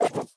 Cybernetic Technology Affirmation 4.wav